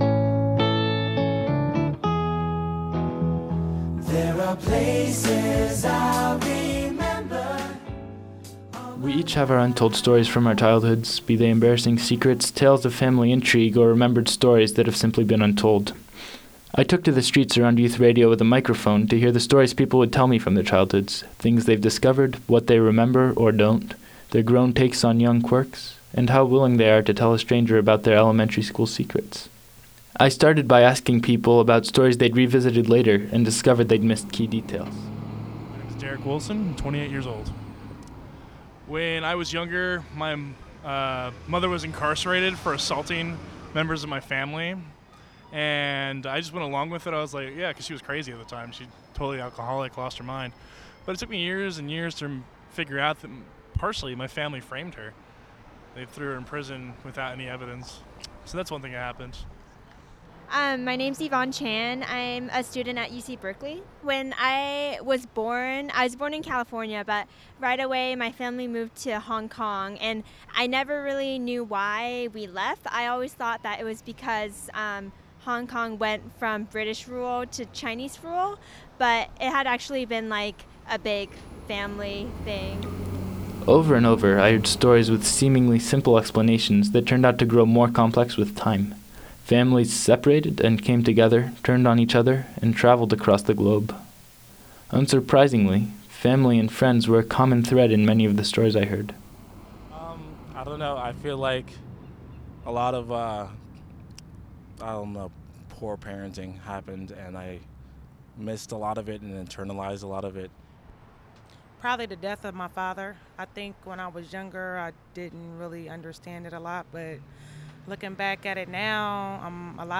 I went out on the street around Youth Radio with a microphone to ask people about their childhood memories: what they’ve revisited, what they now know, and what they were embarrassed about.
Untold_VoxPop.wav